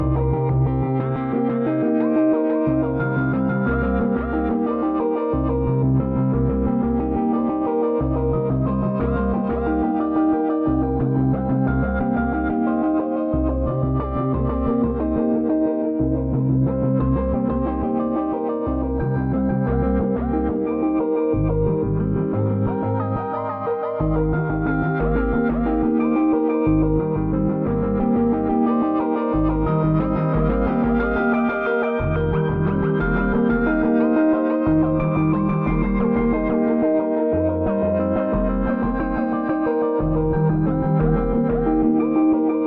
Here is : the Evolver patch the M4L device This sample is an example of polyphonic MIDI run through a single Evolver.